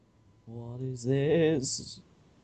What is this (sung)